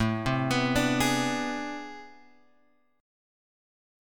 AmM11 chord {5 3 x 4 3 4} chord